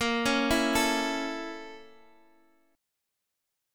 BbmM7b5 chord